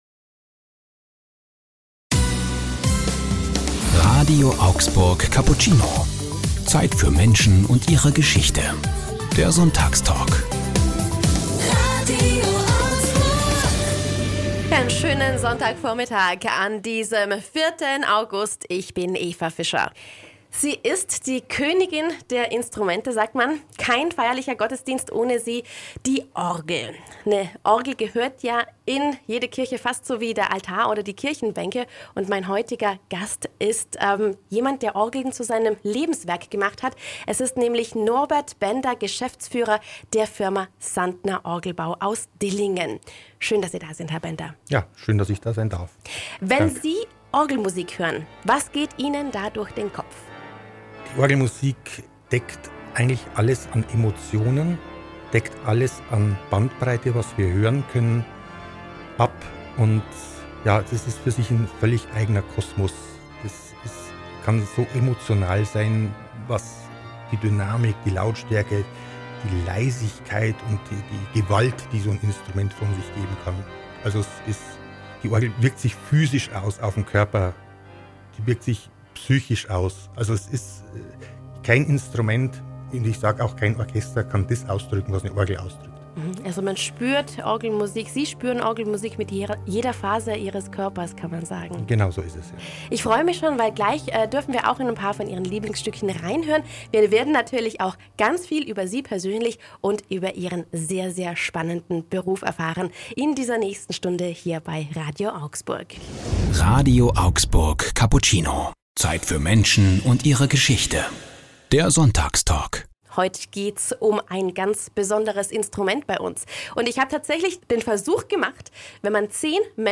Sonntagstalk ~ RADIO AUGSBURG Cappuccino Podcast